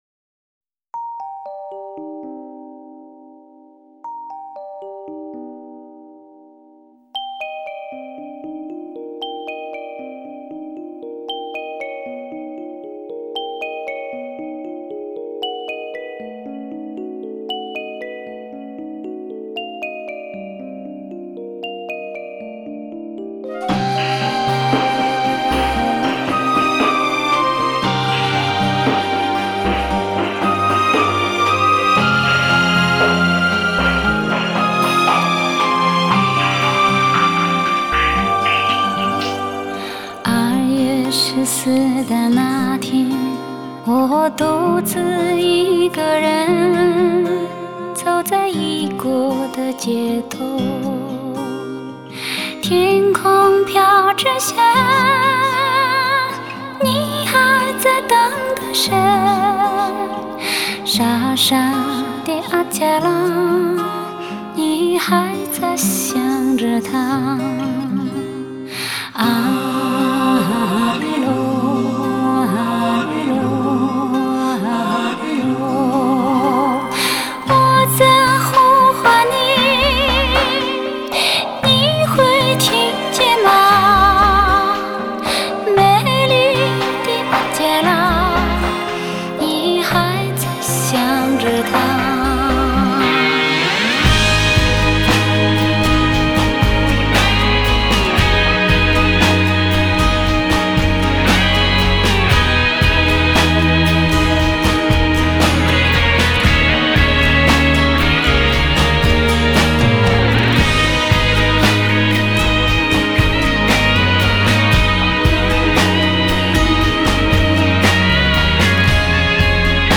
Жанр: Modern Traditional / Chinese pop / Tibetan folk